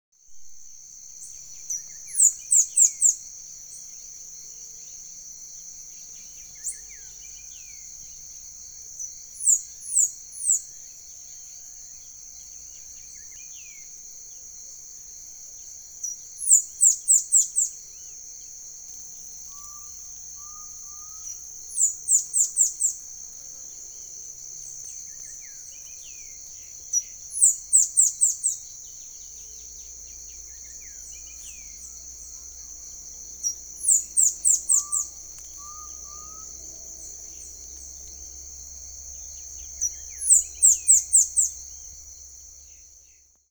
Saffron-billed Sparrow (Arremon flavirostris)
De fondo, Saltator coerulescens , Crotophaga ani, Crypturellus undulatus
Sex: Male
Condition: Wild
Certainty: Observed, Recorded vocal